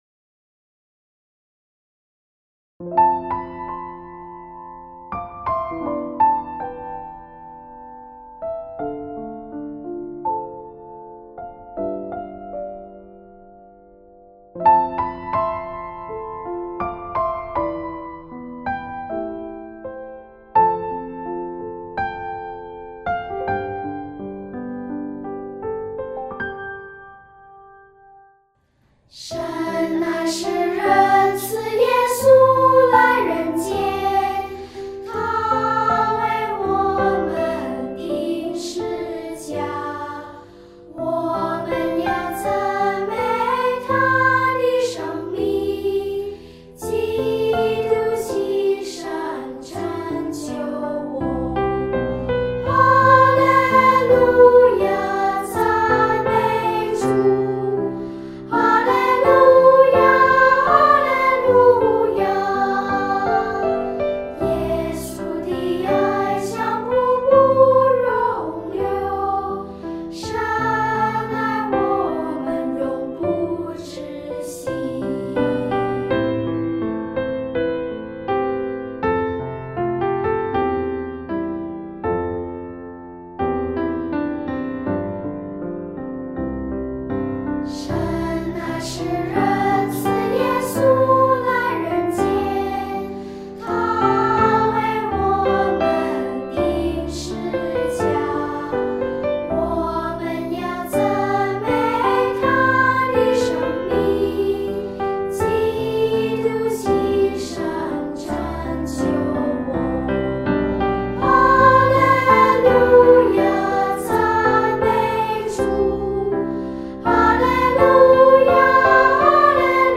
儿童赞美诗|神的爱